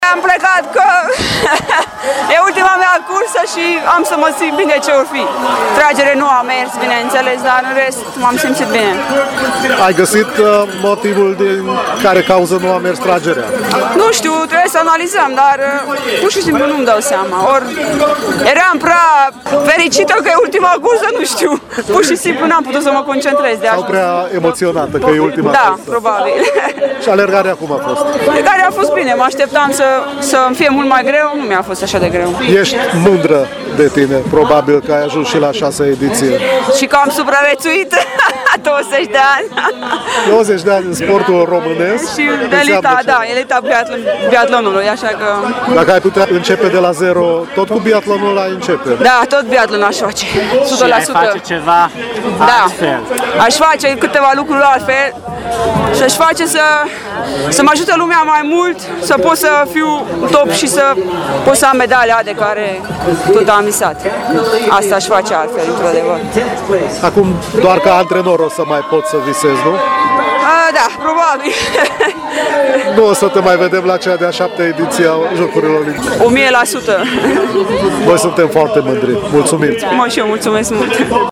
Un interviu cu Eva Tofalvi